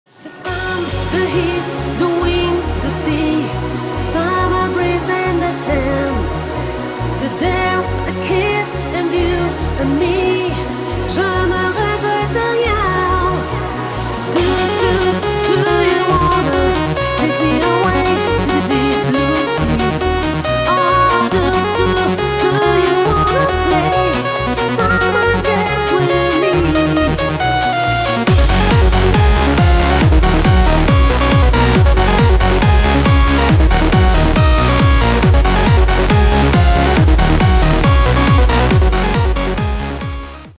• Качество: 256, Stereo
громкие
женский вокал
dance
Electronic
EDM
электронная музыка
нарастающие
энергичные
electro house